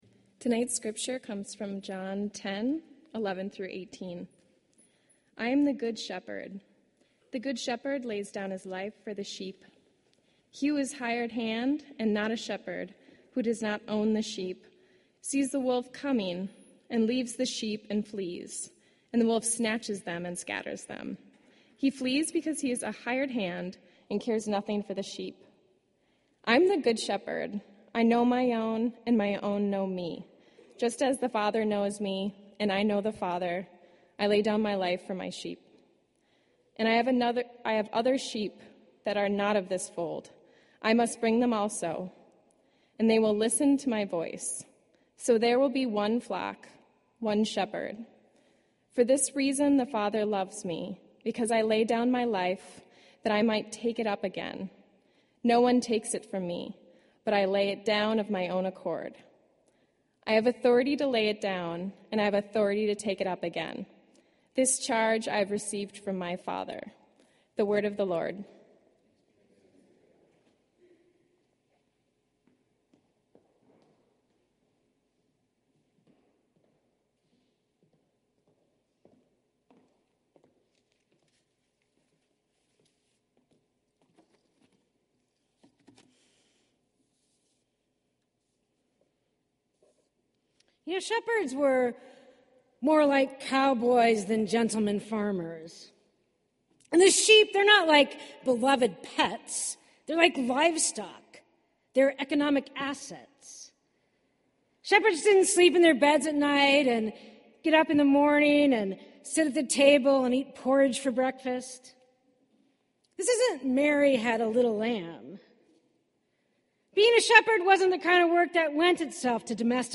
This entry was posted in Sermons .